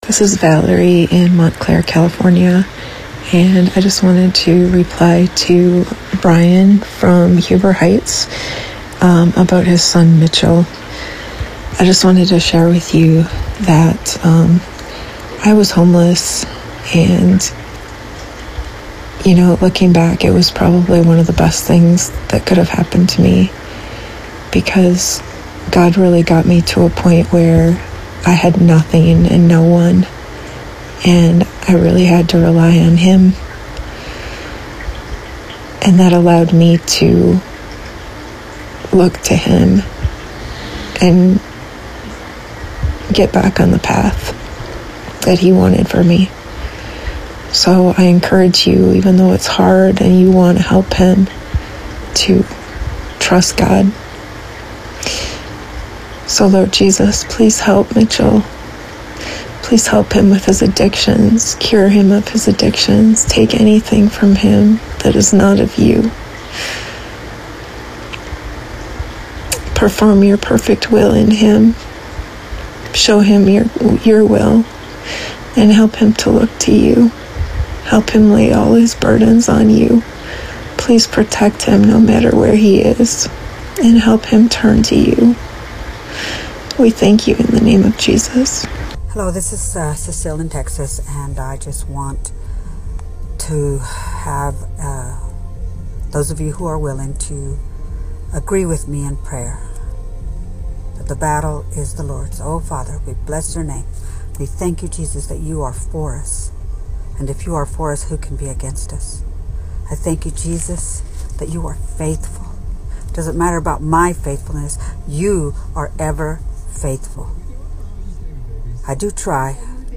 Windfarm, Audio, Daily, Bible, Religion & Spirituality, Christianity, Christian, Spirituality, Spirit, Jesus, Holy, Prayer, God, Community, Global, Scripture